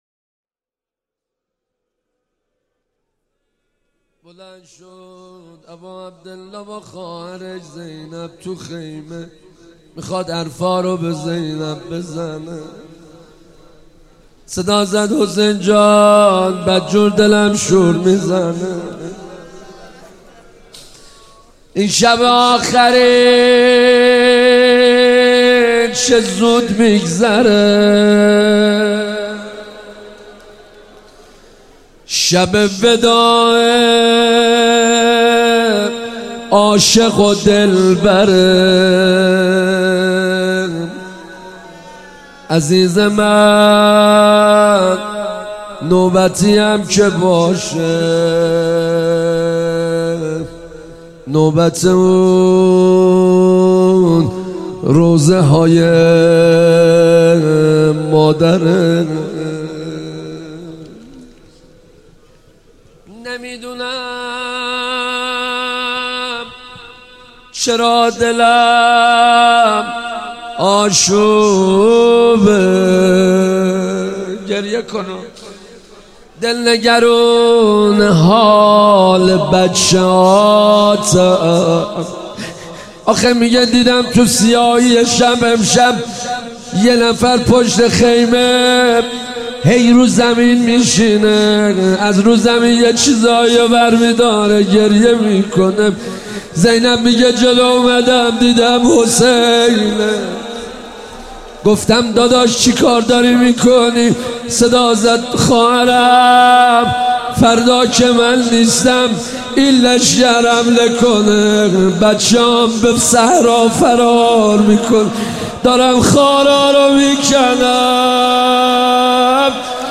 مداحی
روضه امام حسین (علیه السلام) – بخش اول